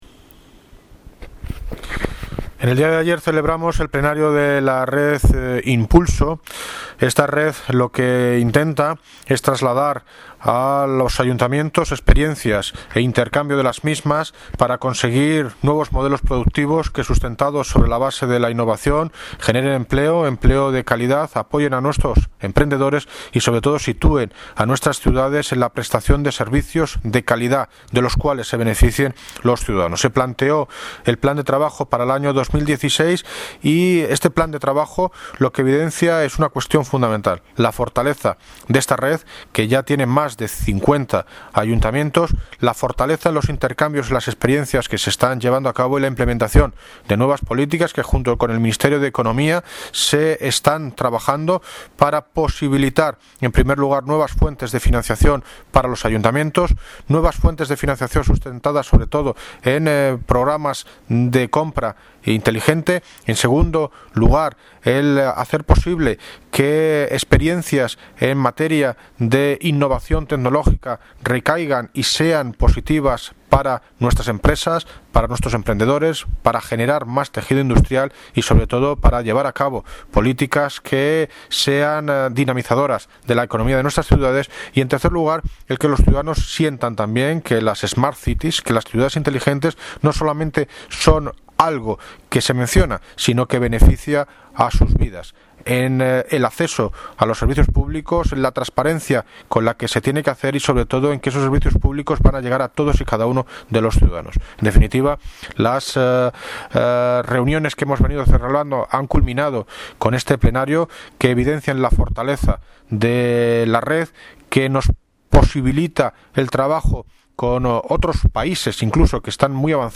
Audio - David Lucas (Alcalde de Móstoles) en Plenario Red Innpulso
Audio - David Lucas (Alcalde de Móstoles) en Plenario Red Innpulso.mp3